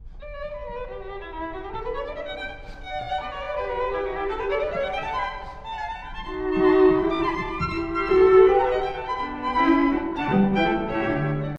↑古い録音のため聴きづらいかもしれません！（以下同様）
Presto
非常に軽快。3楽章に引き続き、弾むようなリズムが印象的です。
また、拍を錯覚させるような作りが特徴的です。
beethoven-sq3-4.mp3